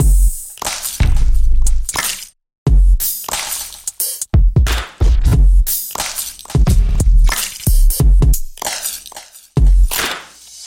描述：放松的罗德斯的旋律
Tag: 80 bpm LoFi Loops Rhodes Piano Loops 4.04 MB wav Key : C FL Studio